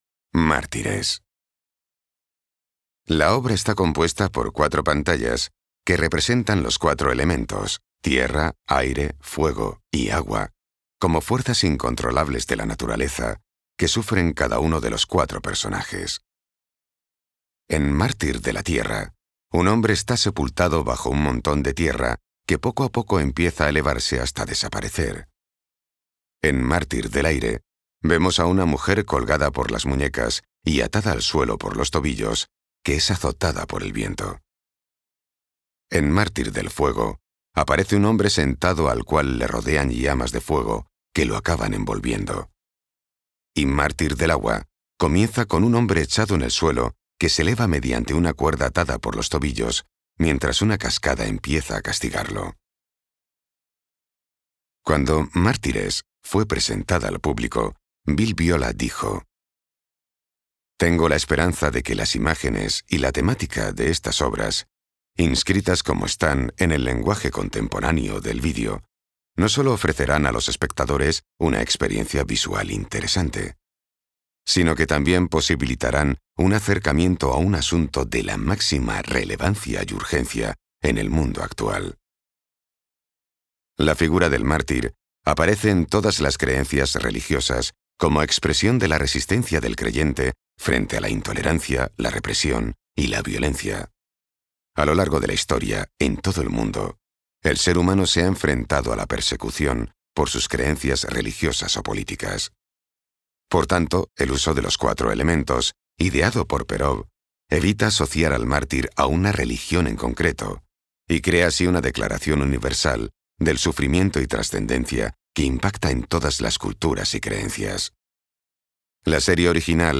También quiero añadir que la página de la exposición cuenta con un apartado llamado "Guía" en el que hay una audioguía gratuita formada por una serie de audios en los que se hablan sobre distintas obras del autor.